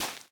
Minecraft Version Minecraft Version 25w18a Latest Release | Latest Snapshot 25w18a / assets / minecraft / sounds / block / big_dripleaf / step5.ogg Compare With Compare With Latest Release | Latest Snapshot